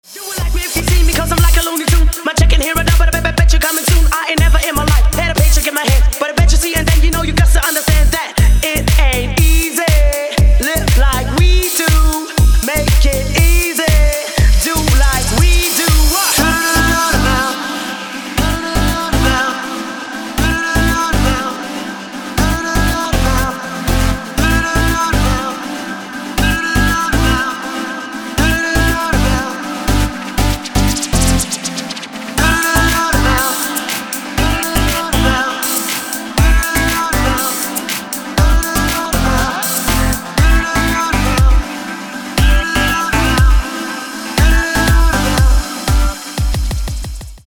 • Качество: 320, Stereo
мужской вокал
громкие
dance
Electronic
EDM
электронная музыка
клавишные